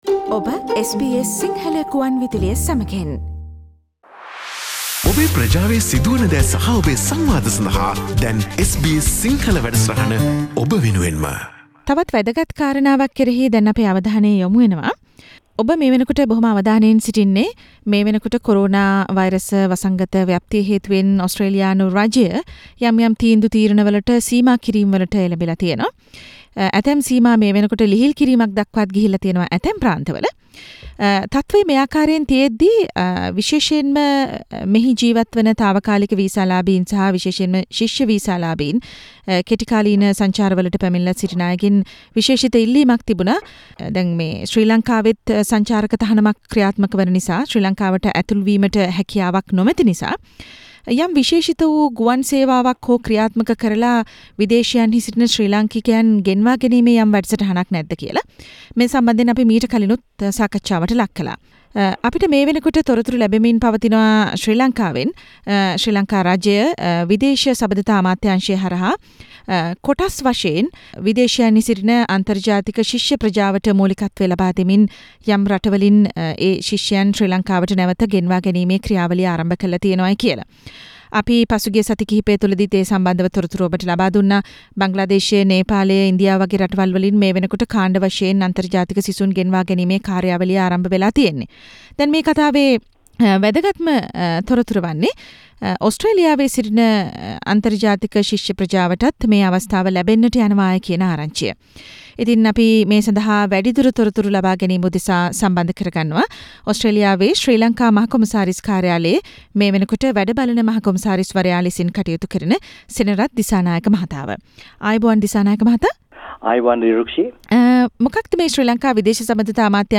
Interview with acting High Commissioner to Australia Mr: Senarath Dissanayake on the details of a special charter flight that has been arranged to take Sri Lankan students from Melbourne to Colombo.